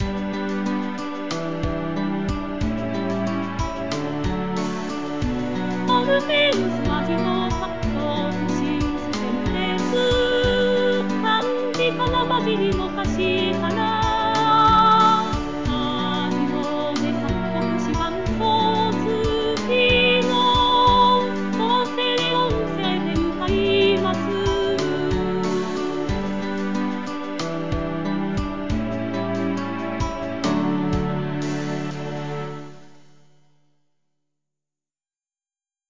合成音声で歌います。